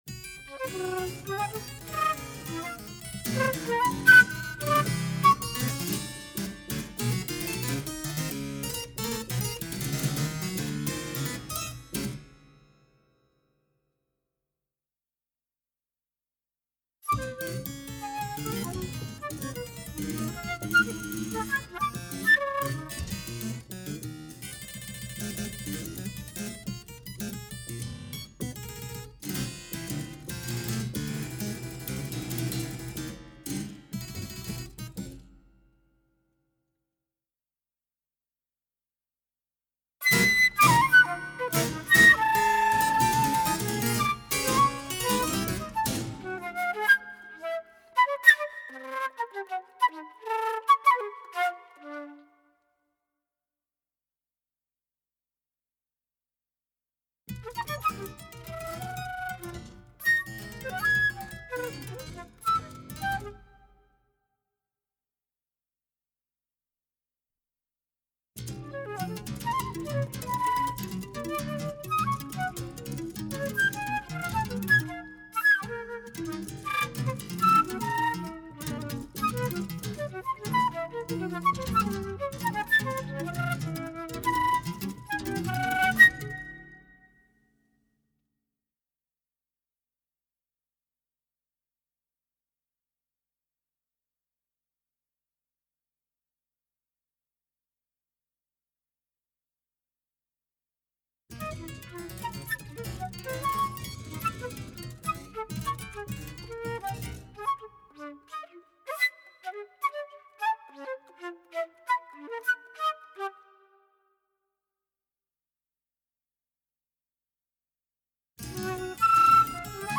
Per flauto e clavicembalo.
registrato presso Studio Suono Ripetta